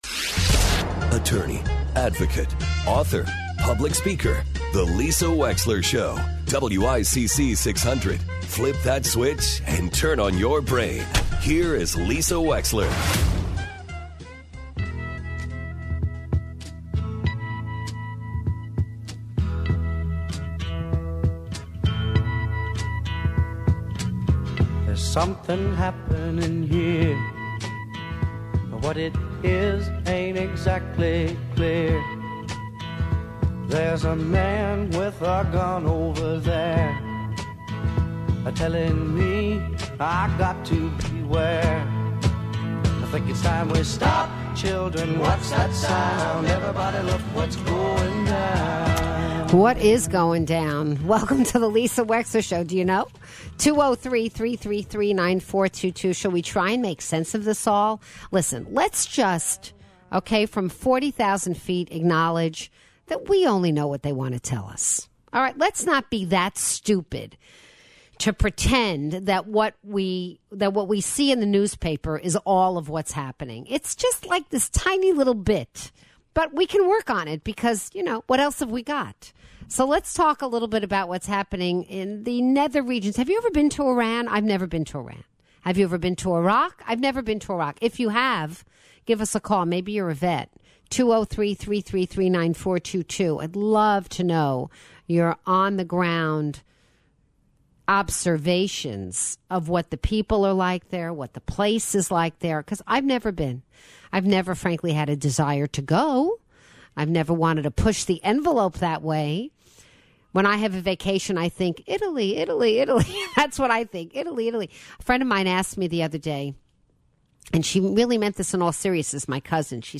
Opening Monologue (2nd Hour)